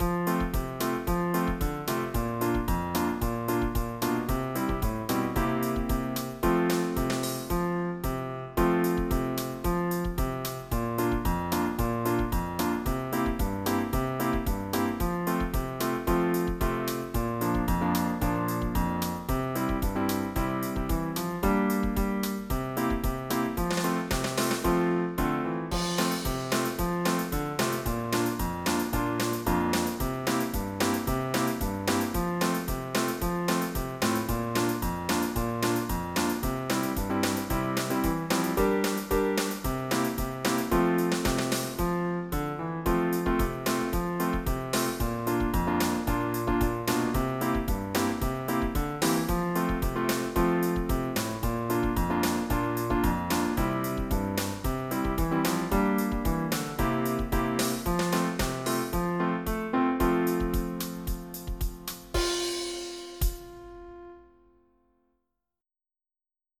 Loop MIDI Music File
Type General MIDI (type 1)